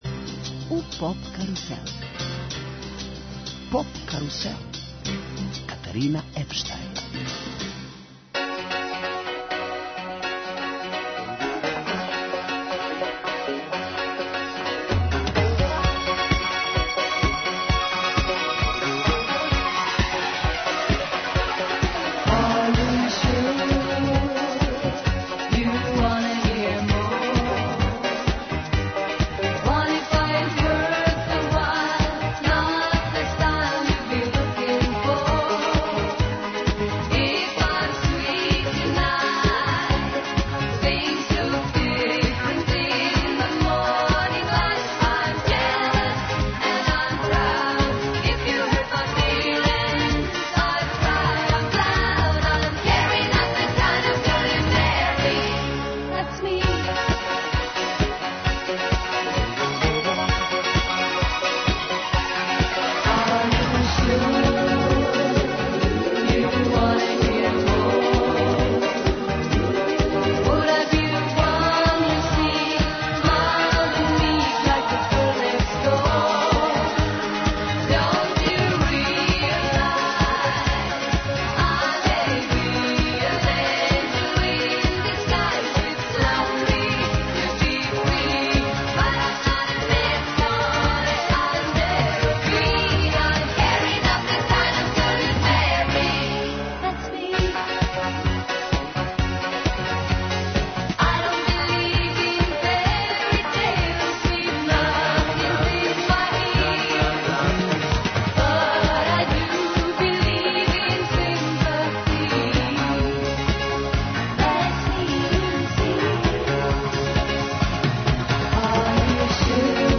Гост нам је новинар